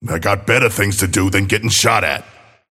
Abrams voice line - I got better things to do than get shot at.